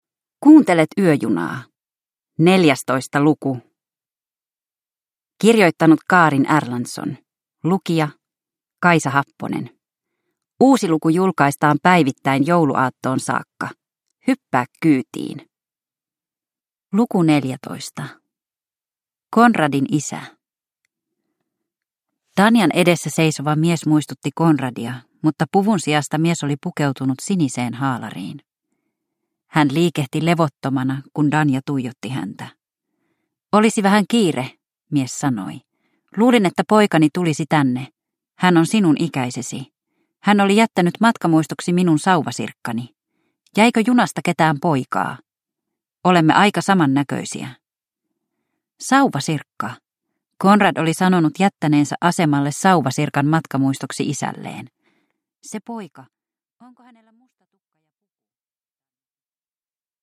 Yöjuna luku 14 – Ljudbok